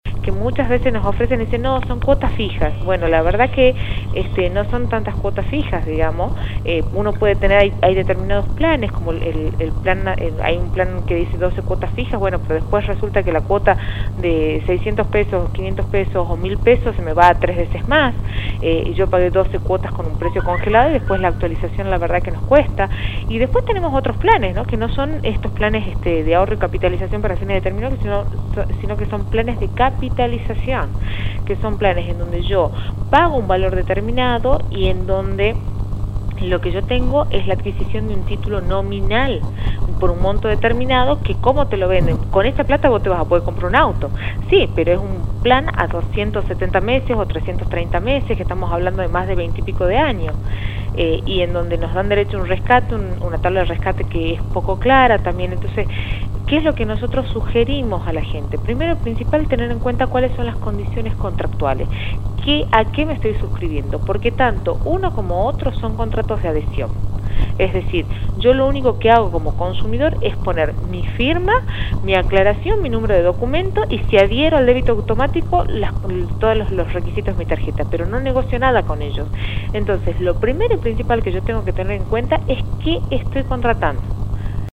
La Secretaría de Defensa del Consumidor de la Provincia de Salta, Carina Iradi habló sobre las más de 300 denuncias en su secretaría relacionadas con planes de ahorro para la adquisición de automóviles:
“Muchas veces nos ofrecen planes y nos dicen que son cuotas fijas y la verdad que no son tan fijas las cuotas. Hay determinados planes como el que dice 12 cuotas fijas pero después resulta que la cuota se va a tres veces más, uno paga doce cuotas con precio congelado pero después la actualización cuesta” expresó Iradi a Radio Dinamo.